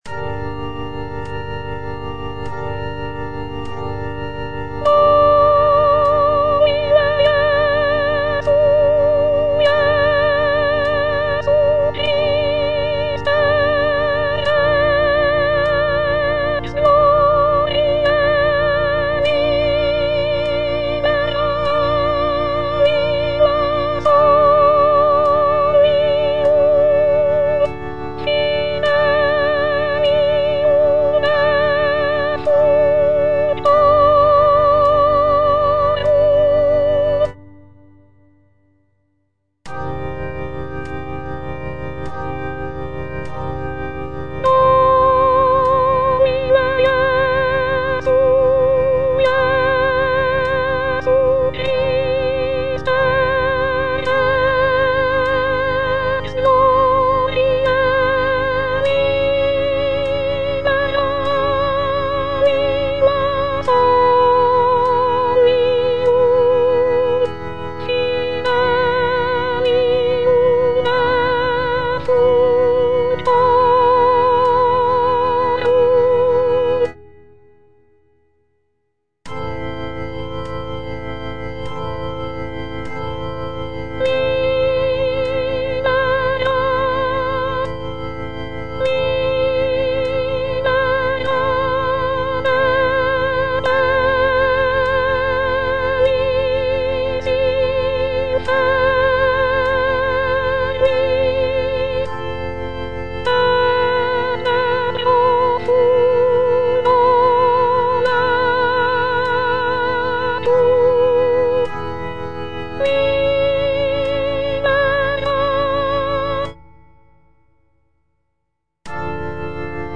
F. VON SUPPÈ - MISSA PRO DEFUNCTIS/REQUIEM Domine Jesu - Soprano (Voice with metronome) Ads stop: auto-stop